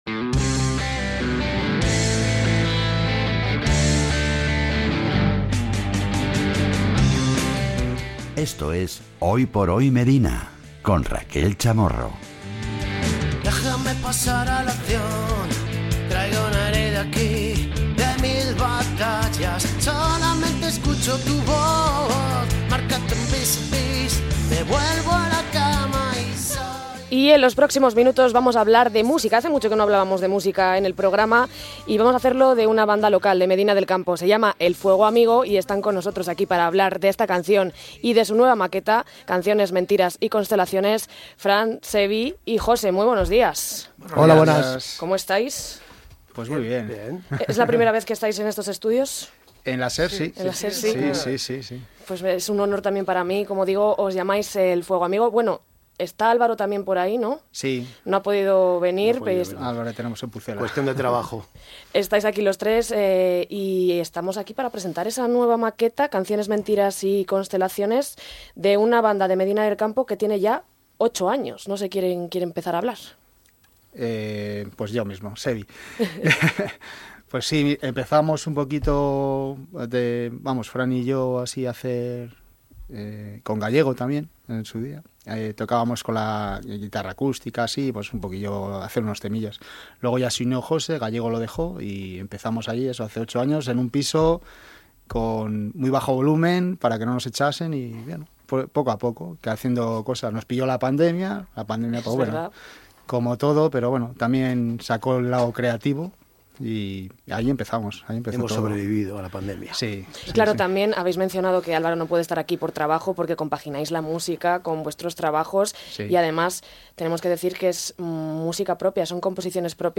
En "Hoy por Hoy Medina", hemos querido hablar con ellos para conocer más sobre el nuevo proyecto, en el que vuelven a reivindicar las composiciones propias y la música como algo esencial en la vida, así como el talento local.